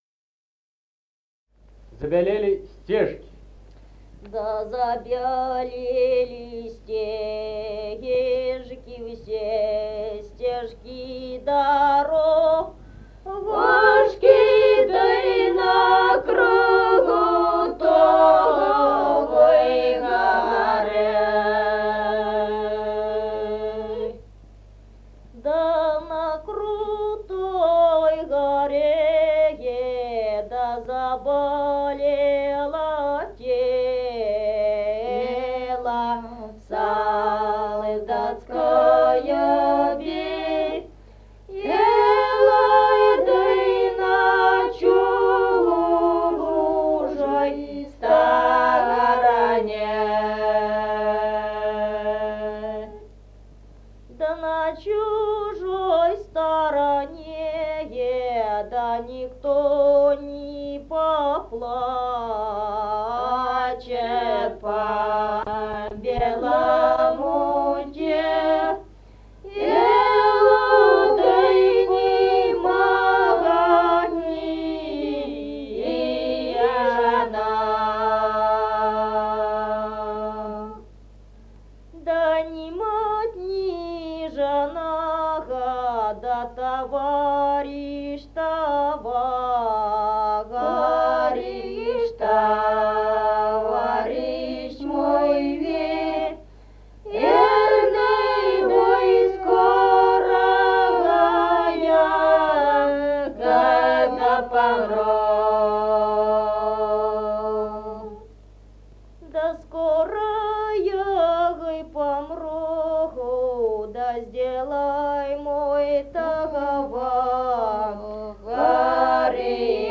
Народные песни Стародубского района «Да забелели стежки», воинская.
1953 г., с. Мишковка.